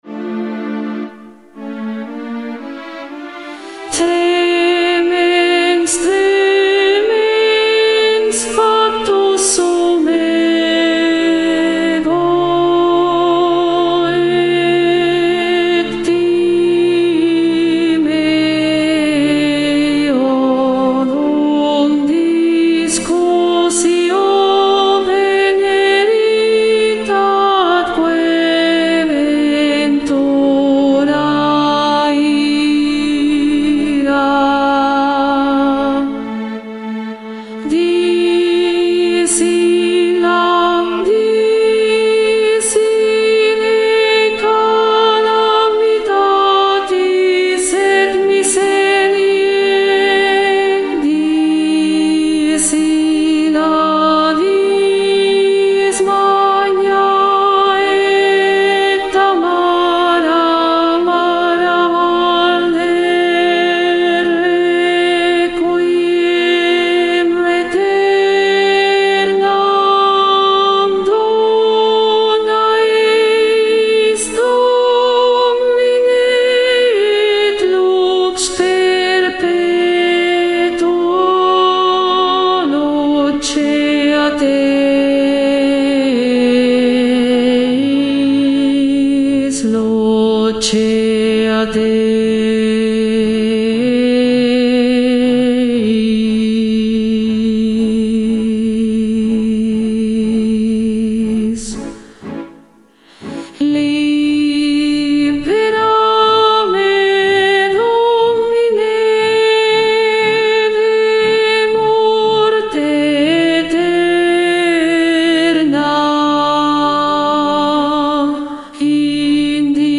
Contralto
Mp3 Profesora
6.-Libera-me-CONTRALTO-VOZ.mp3